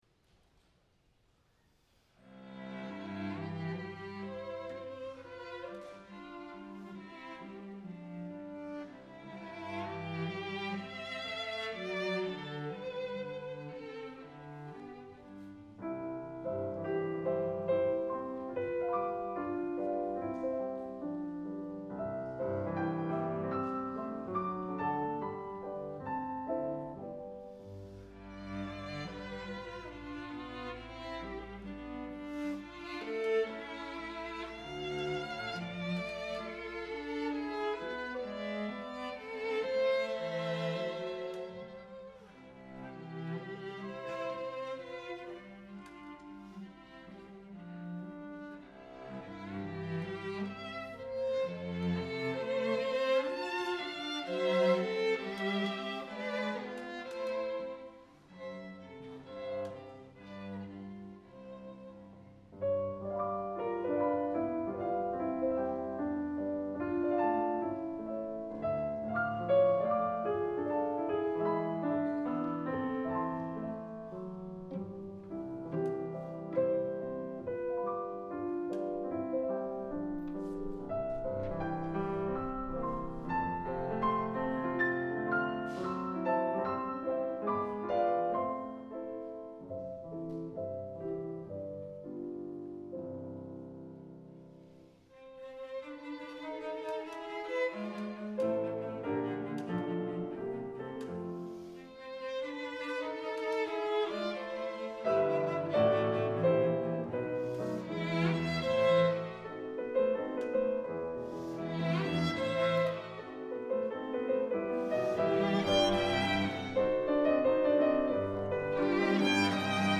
Listen to historic chamber music recordings online as heard at Vermont's Marlboro Music Festival, classical music's most coveted retreat since 1951.
Piano Trio in C Minor, Op. 101
03_andante_grazioso.mp3